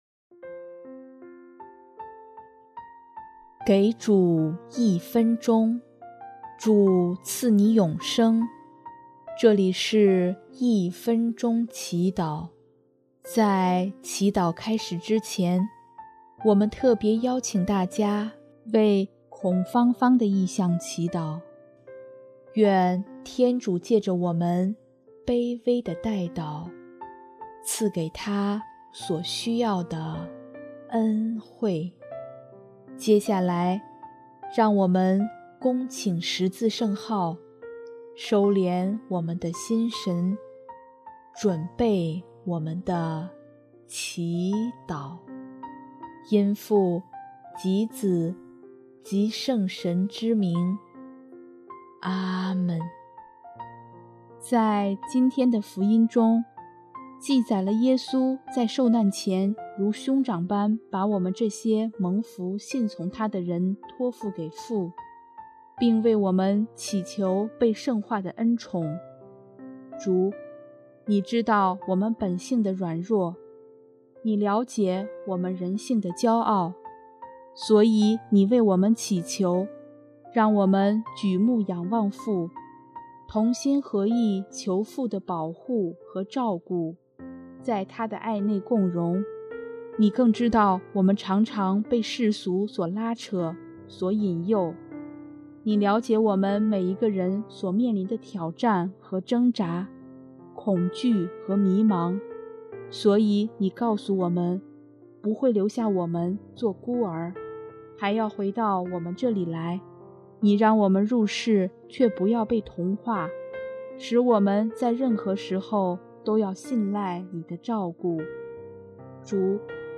【一分钟祈祷】|6月4日 我不会留下你们做孤儿
音乐：第四届华语圣歌大赛参赛歌曲《重返乐园》